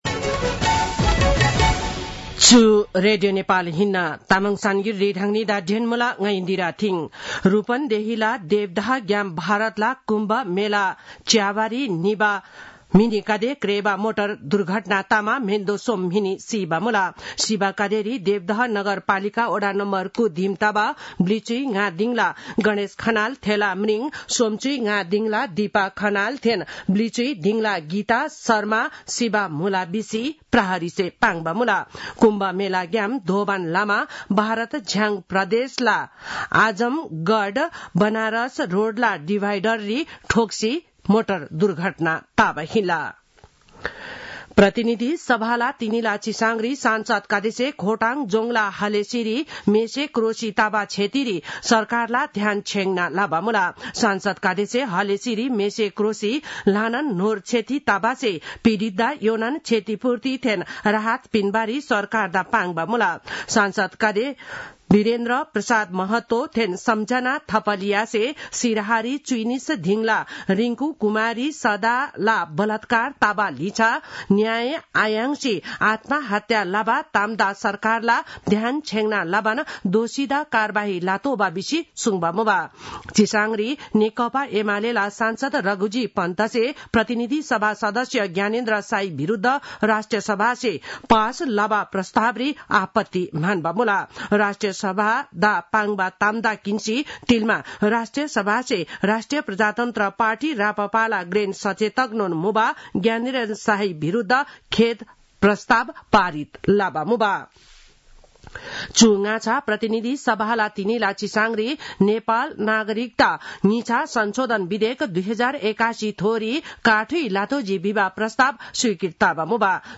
Tamang-news-11-05.mp3